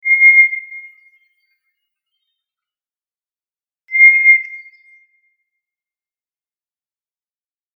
トラツグミ.mp3